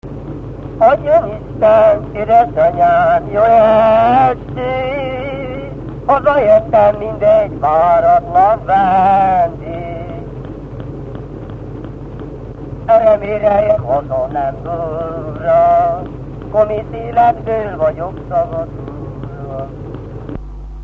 Erdély - Csík vm. - Csíkrákos
Stílus: 3. Pszalmodizáló stílusú dallamok
Kadencia: 7 (b3) b3 1